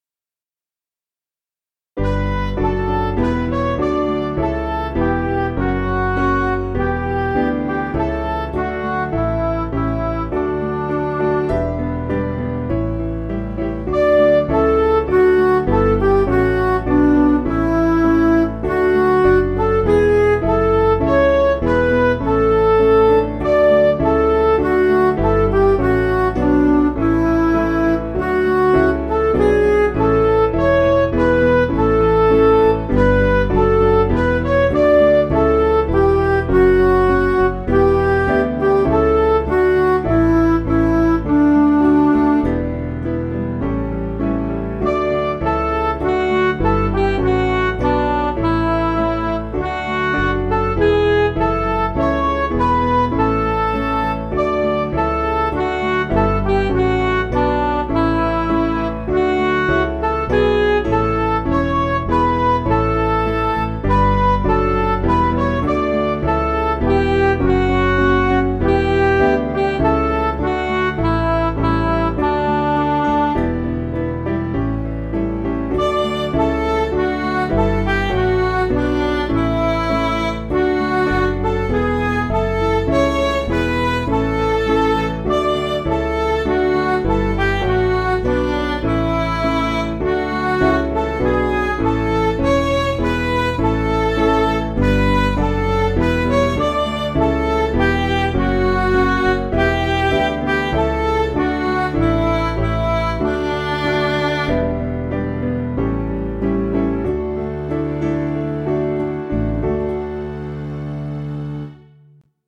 Piano & Instrumental
Midi